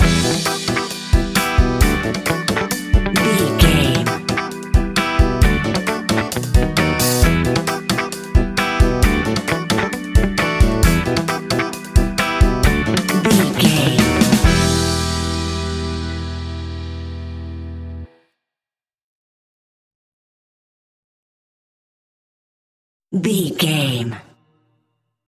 A groovy piece of upbeat Ska Reggae!
Aeolian/Minor
Fast
laid back
off beat
drums
skank guitar
hammond organ
percussion
horns